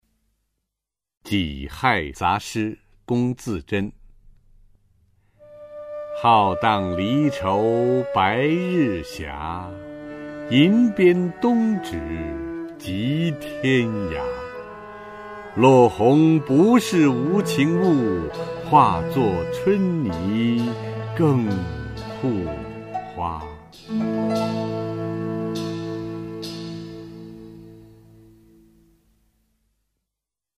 [清代诗词诵读]龚自珍-己亥杂诗（浩荡）(男) 配乐诗朗诵